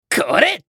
熱血系ボイス～戦闘ボイス～
【氷の魔法1】